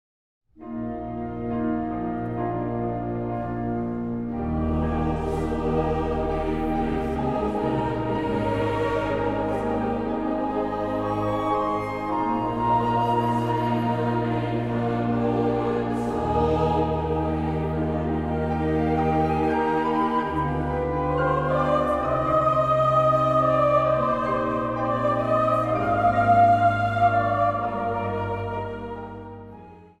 orgel
piano
panfluit en dwarsfluit.
2 stemmen
Zang | Jongerenkoor